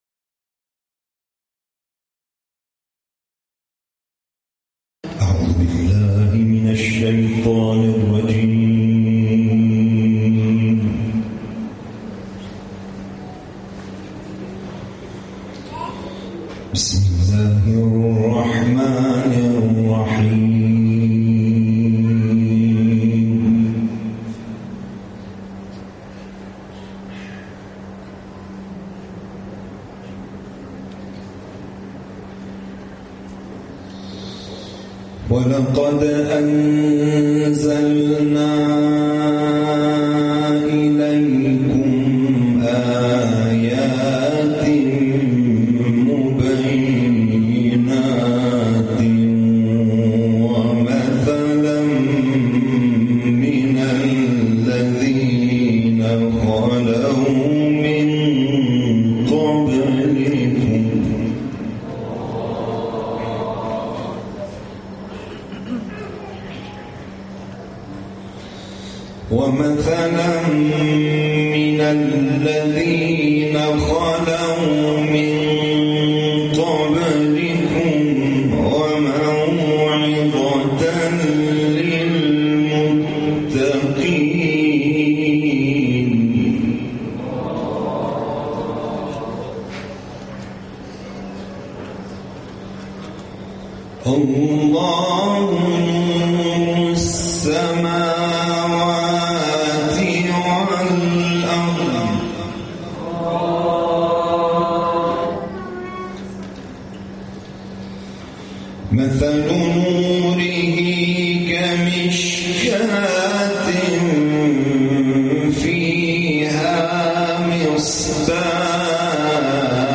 این تلاوت طی خردادماه، در کرسی تلاوت شهر شوشتر خوزستان اجرا شده است.